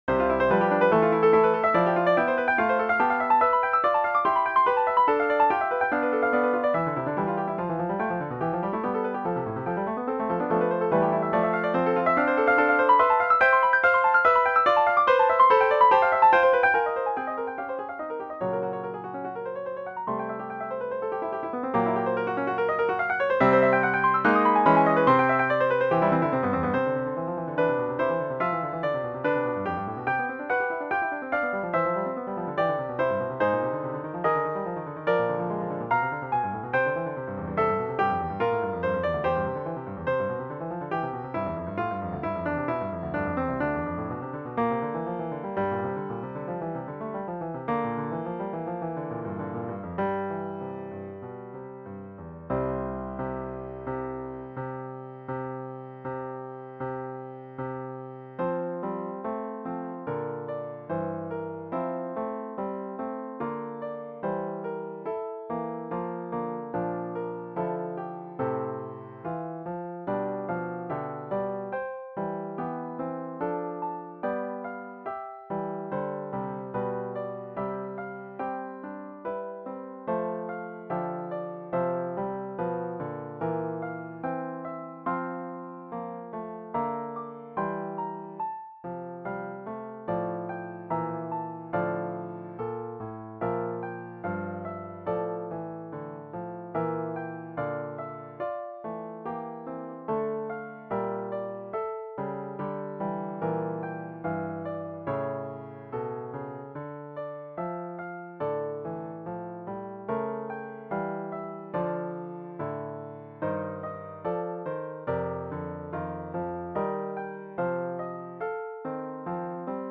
Prelude in C major - Piano Music, Solo Keyboard - Young Composers Music Forum
well, composed 3 preludes, 21 to go... hope you like this one, quite sudden change in keys, pls suggest how to do it in a better.